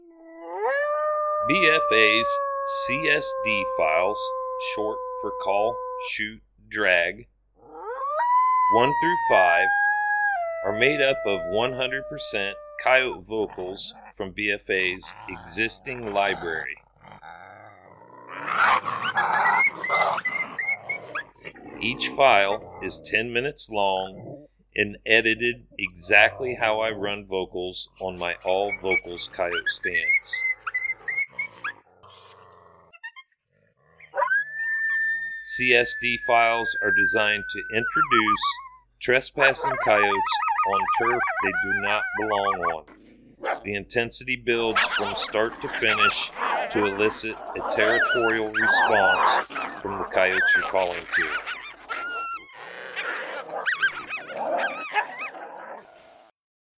Each BFA CSD File is 10 minutes in length, made up from our most popular Coyote Howls, Coyote Social Vocalizations and Coyote fights.